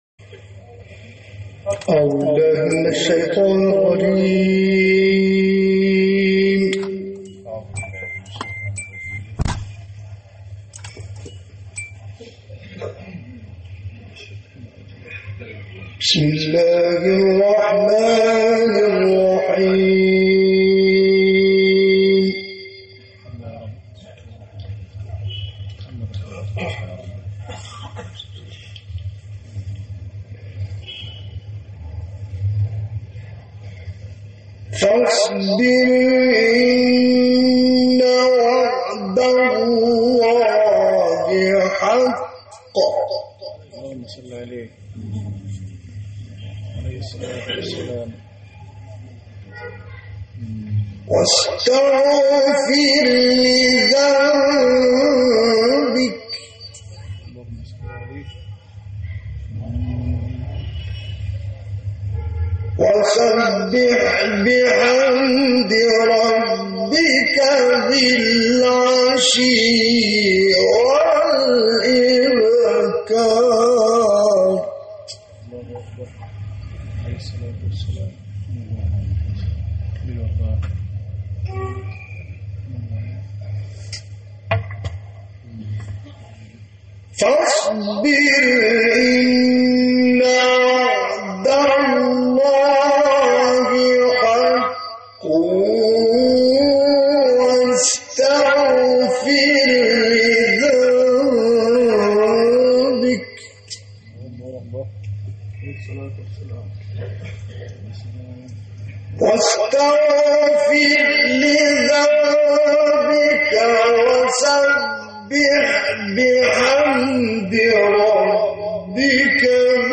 محفل مشترک «غلوش» و «حصان» در بحیره مصر
گروه شبکه اجتماعی: در محفل قرآنی مشترکی که در سال 1987 میلادی در استان البحیره مصر برگزار شده بود، محمد عبدالعزیز حصان و راغب مصطفی غلوش به تلاوت آیاتی از کلام الله مجید پرداختند.
محفل مشترک